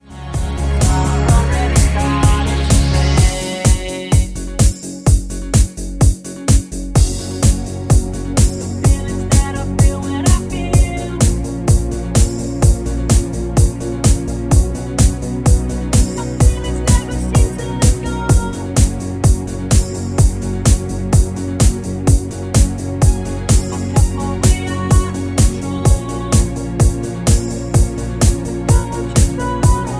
A#
Backing track Karaoke
Pop, Musical/Film/TV, 2000s